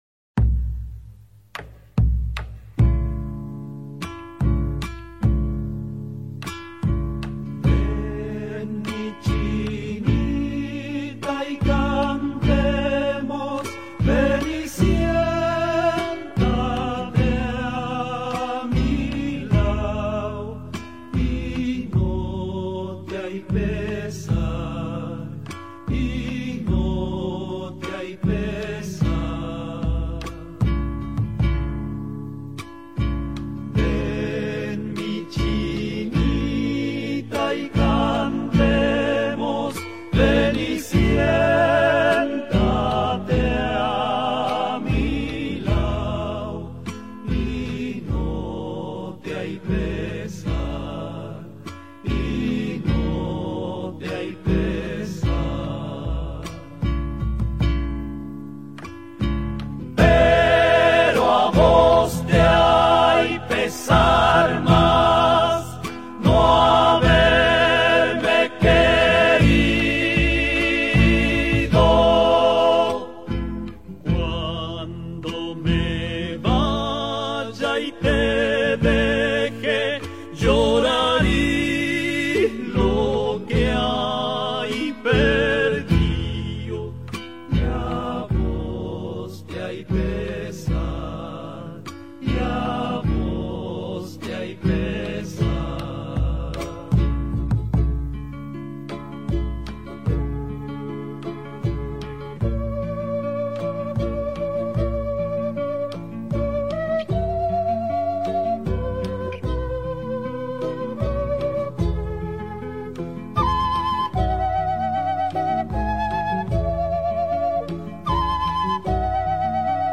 انگار فقط صدای سوز باد رو می‌شنوی.
با صدای فلوت سحرآمیز کِنا (Quena) تداعی می‌شه.